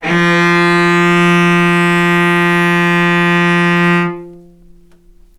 vc-F3-ff.AIF